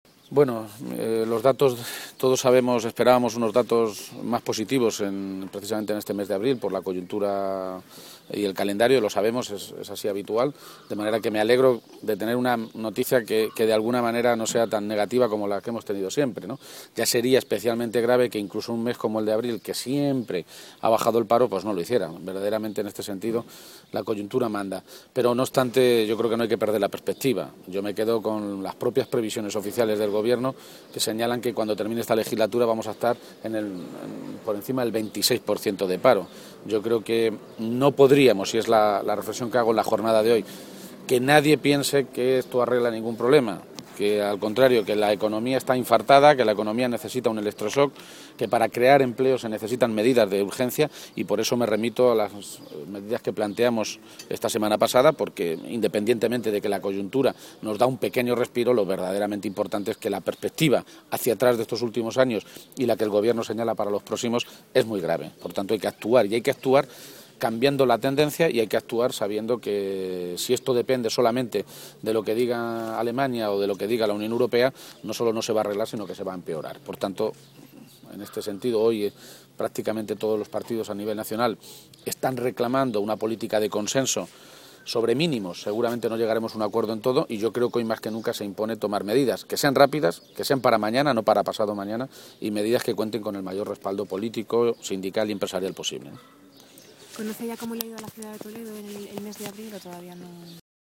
Emiliano García-Page, Secretario General del PSOE de Castilla-La Mancha
El líder de los socialistas de Castilla-La Mancha ha hecho estas declaraciones esta mañana, en Toledo, a preguntas de los medios de comunicación, y ha vuelto a ofrecer al Gobierno regional el decálogo de medidas urgentes que él mismo presentaba el pasado jueves para poner en marcha un Plan de Empleo que cree, de manera inmediata, 42.500 puestos de trabajo en Castilla-La Mancha.
Cortes de audio de la rueda de prensa